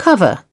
8. cover (v) /ˈkʌvə(r)/ : bao phủ, đề cập